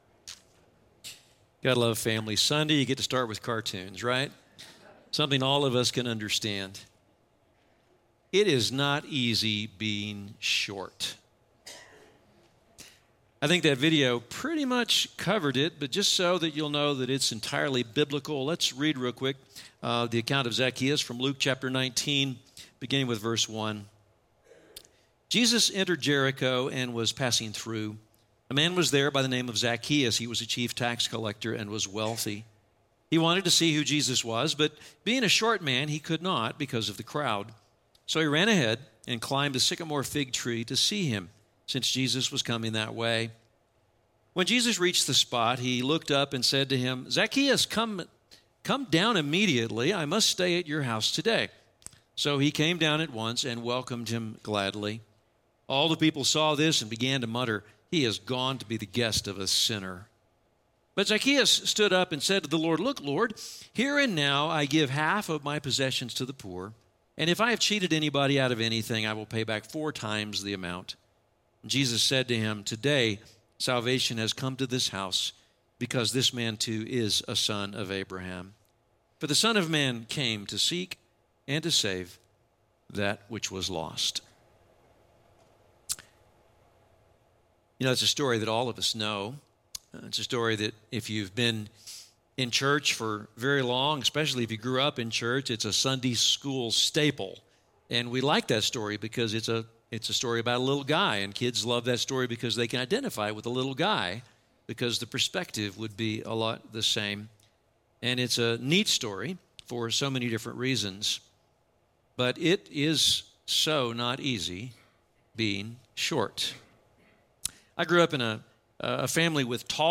A Short Sermon About a Short Man Who Grew Tall